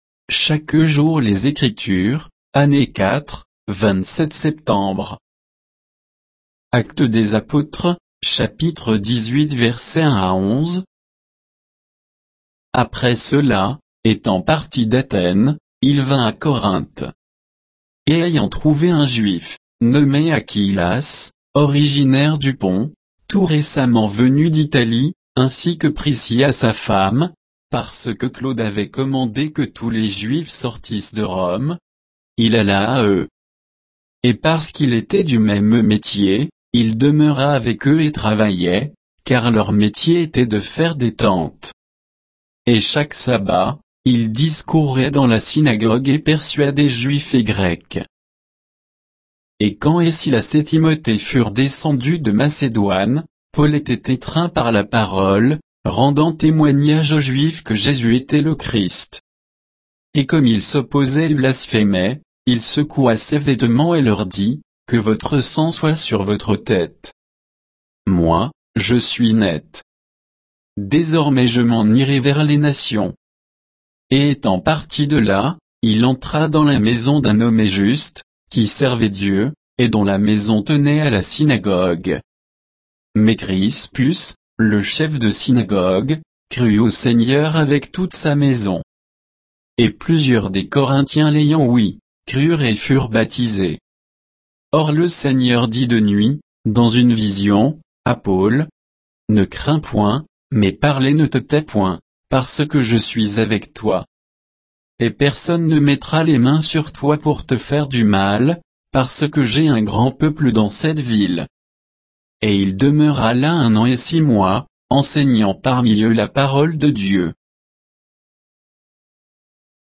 Méditation quoditienne de Chaque jour les Écritures sur Actes 18, 1 à 11